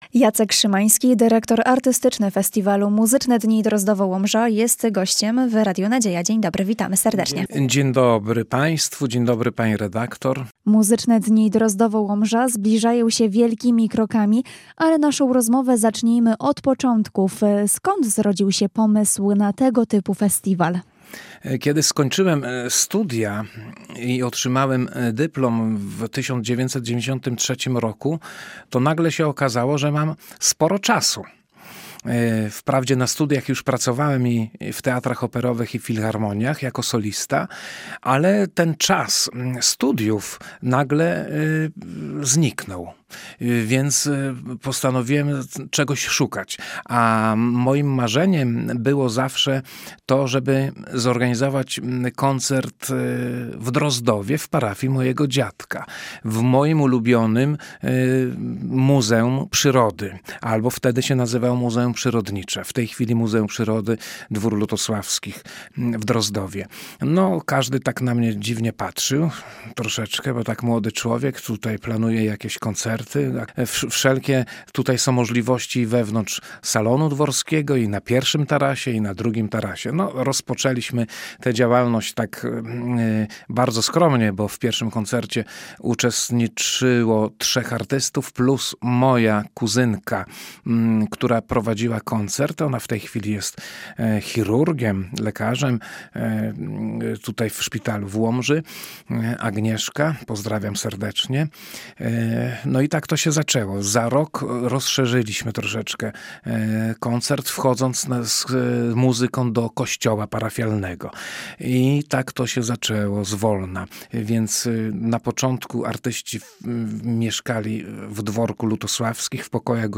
Rozmowa RN